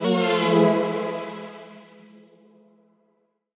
Index of /musicradar/future-rave-samples/Poly Chord Hits/Ramp Down
FR_ZString[dwn]-E.wav